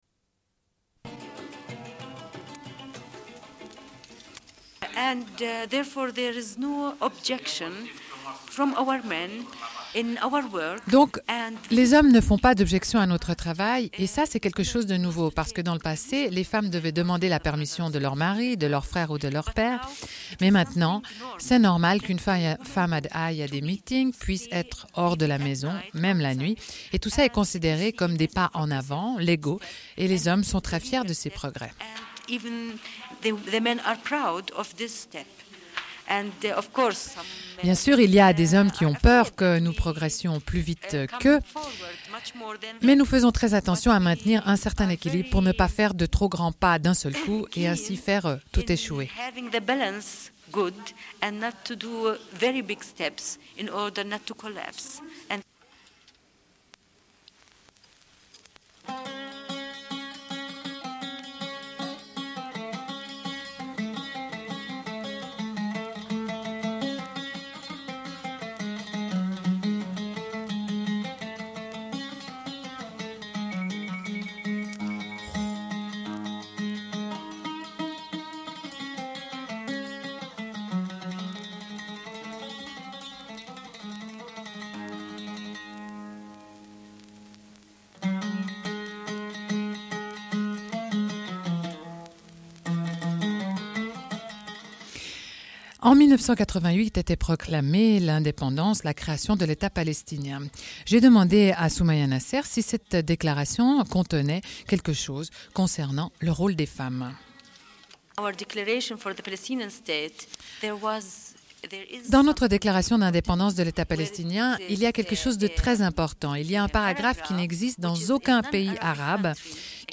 Une cassette audio, face A00:31:20
Cette émission a lieu à l'occasion de la Conférence de Madrid.